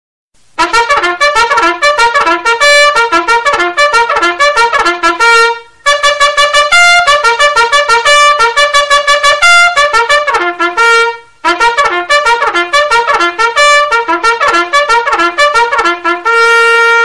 Musiques et Sons intermédiaires (Forts)
10-trumpet.mp3